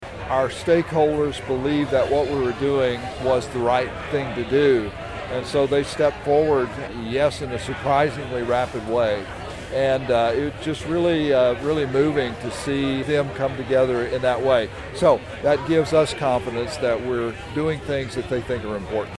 A groundbreaking ceremony for a new Agronomy Research and Innovation Center at Kansas State University had to be moved indoors Monday, due to rain, leading to a standing-room only crowd of guests inside the university’s Agronomy Education Center.